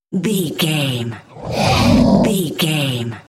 Whoosh creature growl fast
Sound Effects
Fast
ominous
eerie
whoosh